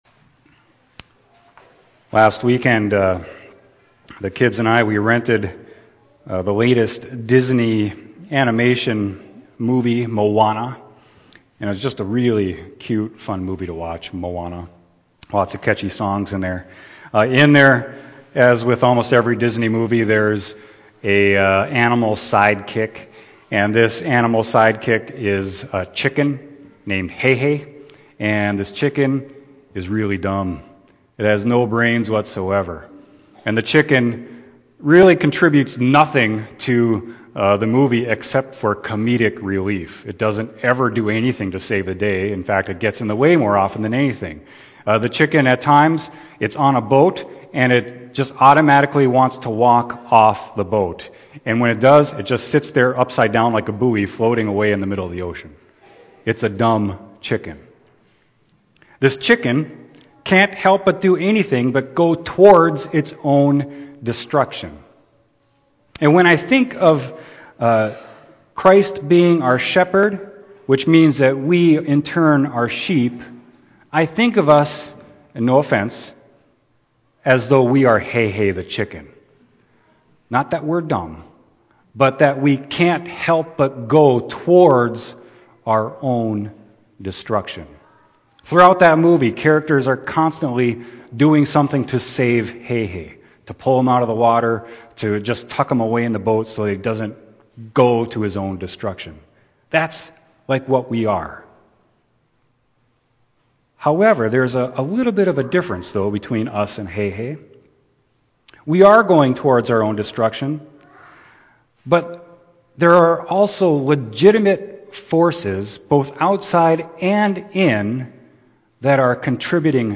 Sermon: John 10.7-18